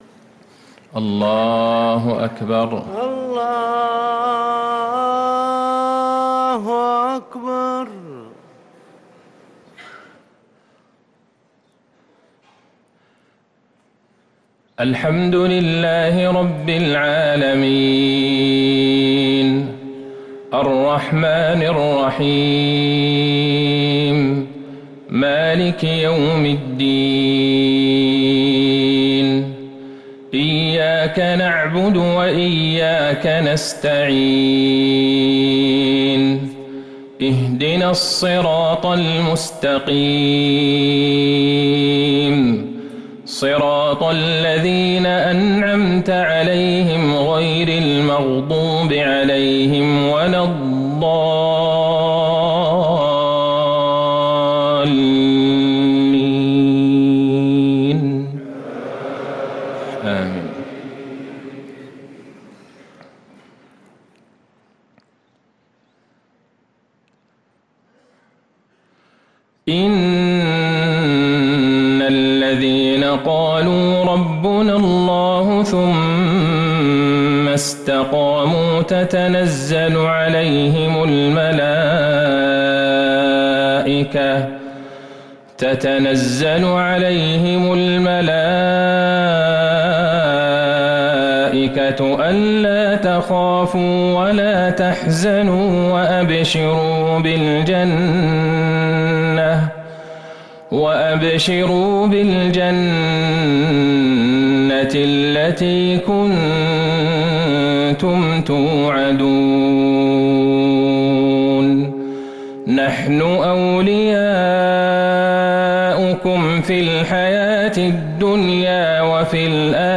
صلاة المغرب للقارئ عبدالله البعيجان 3 ربيع الآخر 1445 هـ